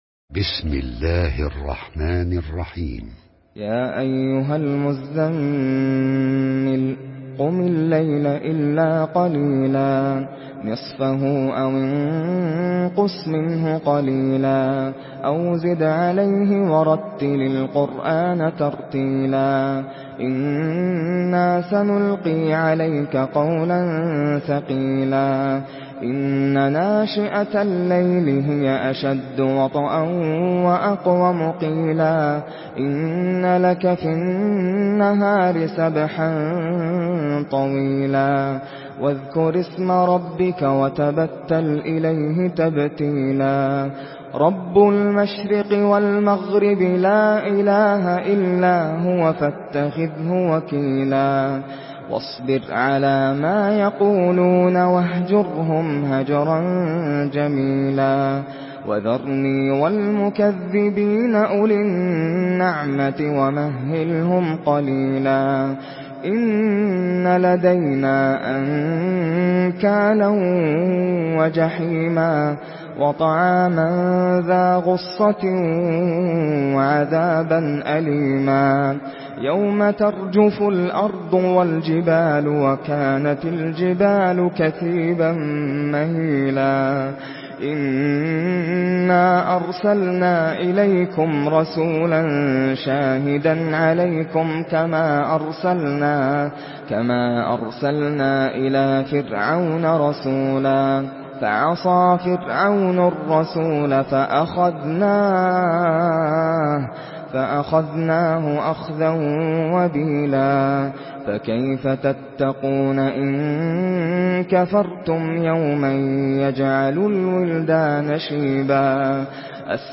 Surah আল-মুযযাম্মিল MP3 in the Voice of Nasser Al Qatami in Hafs Narration
Surah আল-মুযযাম্মিল MP3 by Nasser Al Qatami in Hafs An Asim narration.